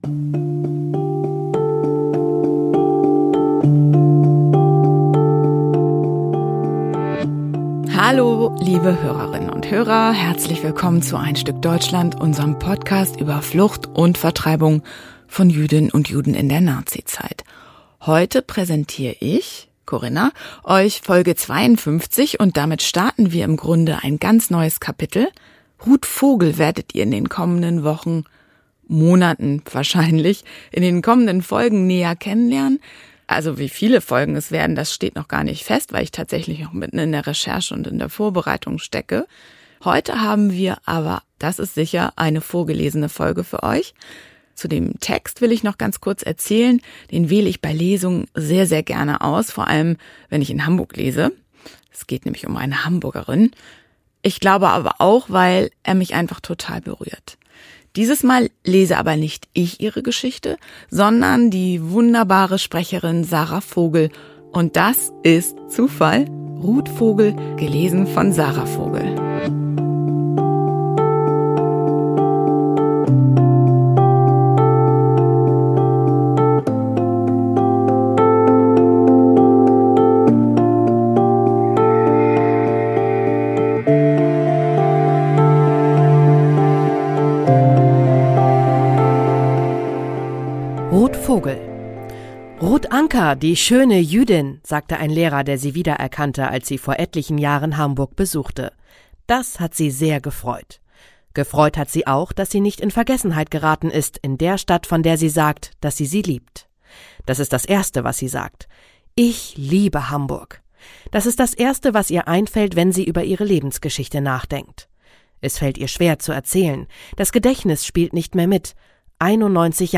vorgelesen von